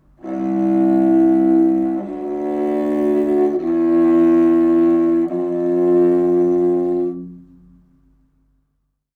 Sounds on strings in Baritone Qeychak are like this:
4th string in 1st position: